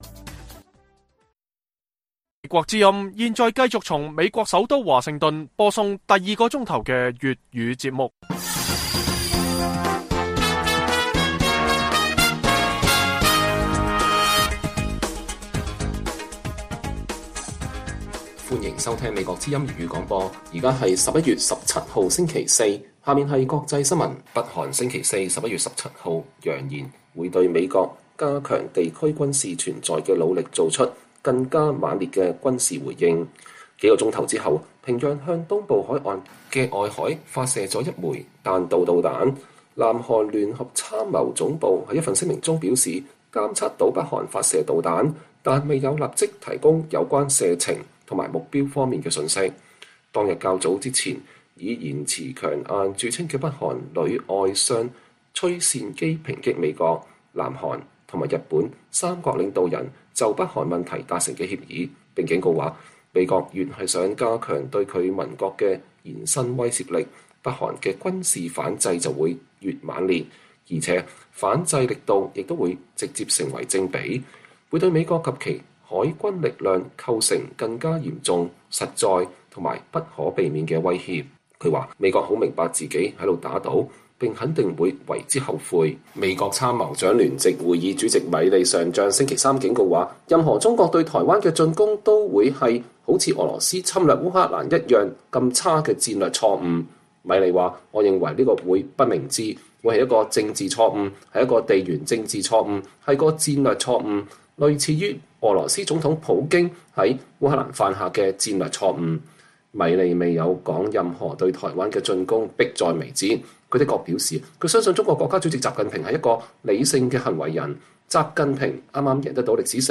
粵語新聞 晚上10-11點: 北韓再射導彈並警告美國